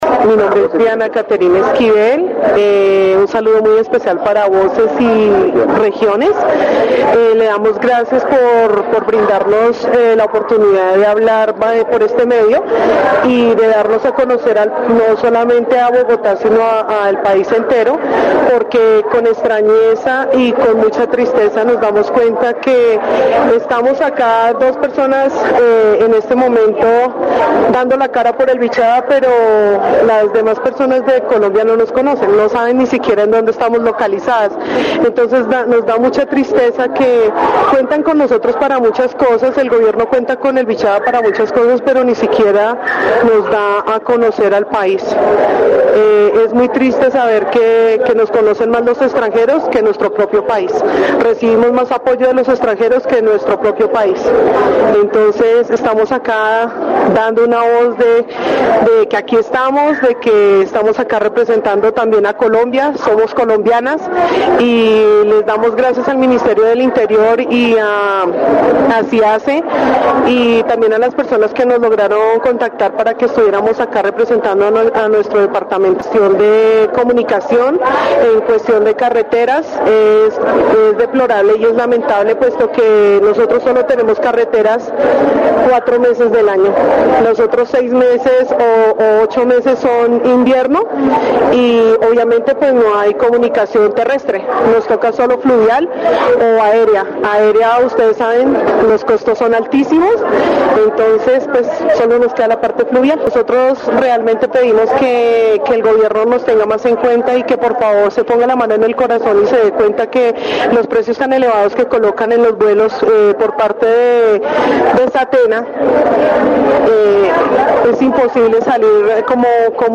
Entrevista sobre la falta de conocimiento y apoyo del gobierno hacia Vichada, destacando las dificultades de transporte y comunicación que enfrentan los habitantes de la región.